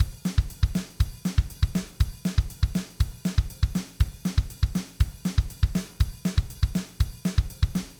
Simply import into Logic Pro and choose a drum kit instrument from the library—all of these were created using the SoCal instrument.
D-Beat
A punk and hardcore staple, named after Discharge, who popularized it.
D-Beat-1.wav